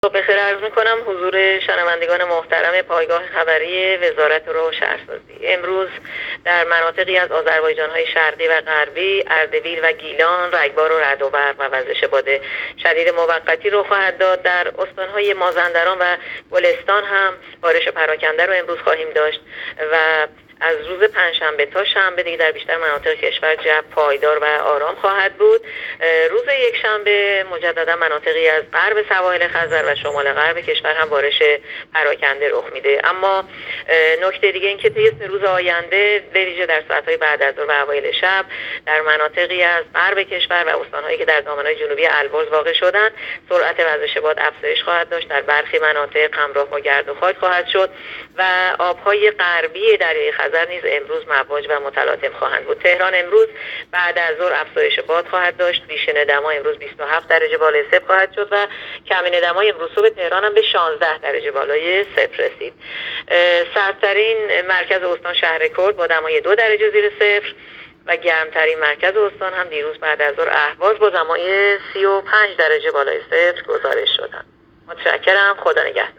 گزارش رادیو اینترنتی پایگاه‌ خبری از آخرین وضعیت آب‌وهوای ۳۰ مهر؛